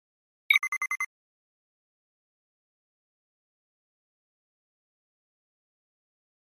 Flash Readout High Frequency Chirp with Multiple Beep Tail